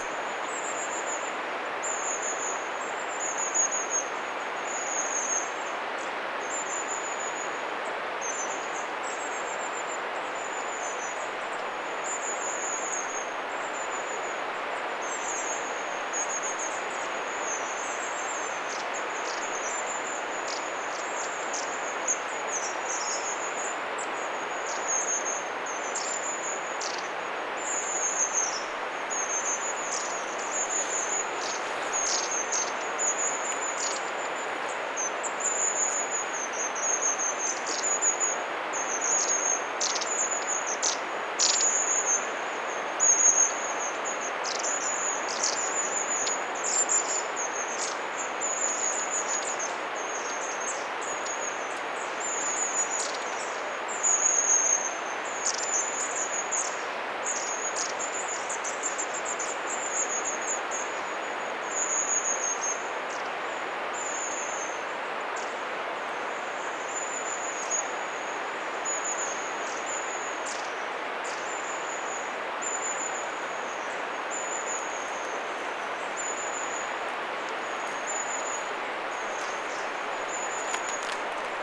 pyrstötiaisia saaressa, 1
pyrstotiaisia_saaressa.mp3